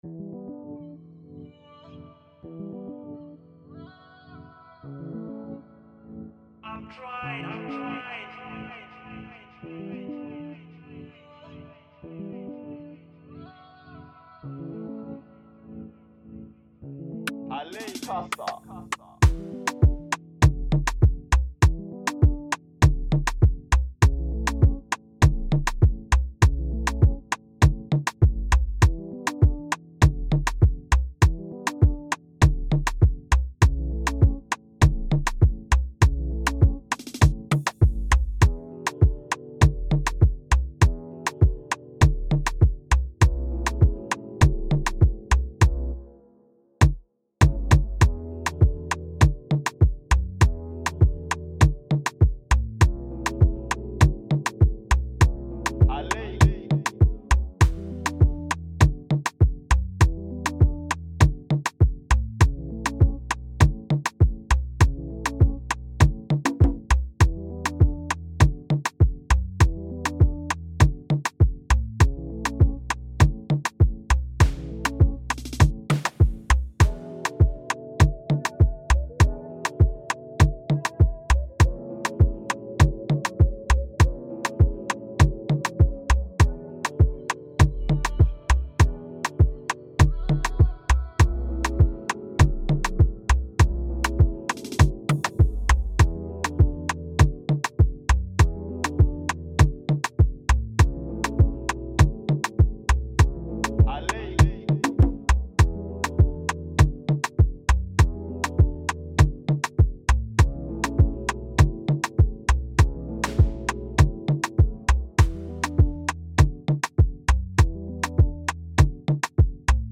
free beat instrumental
Download & Listen to the 2023 Afro-piano